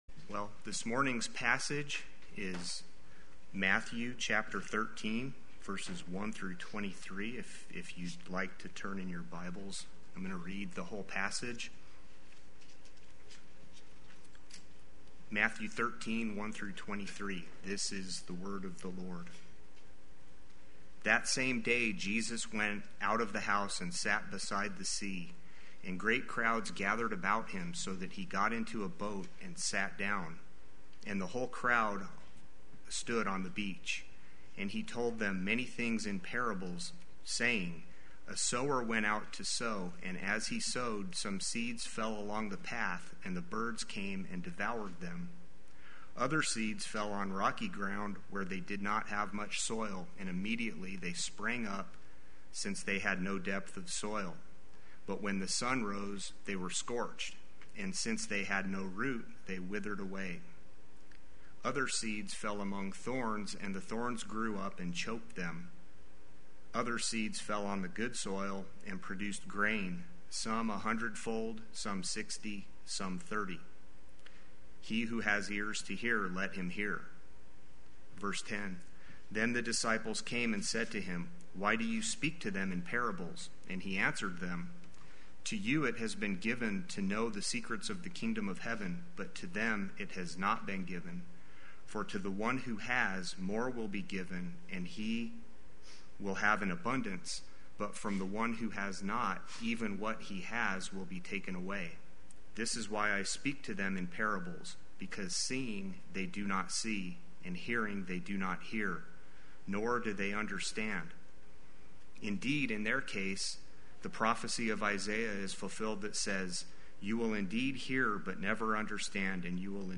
Play Sermon Get HCF Teaching Automatically.
and the Soil Sunday Worship